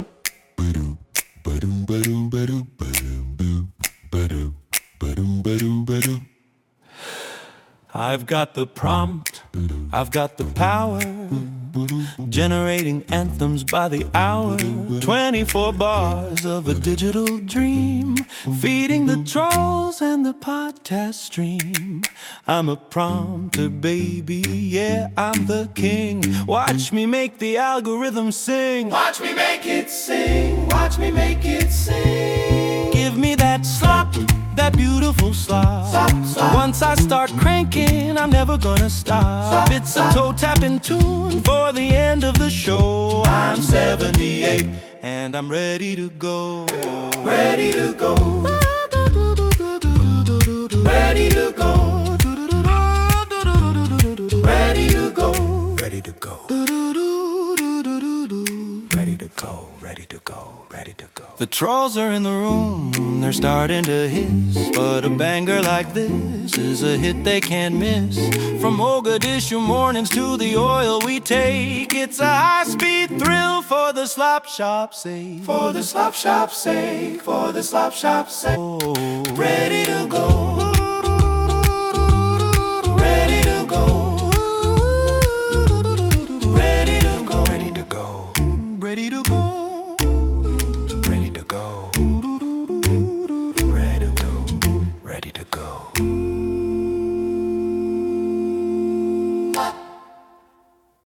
End of Show Mixes: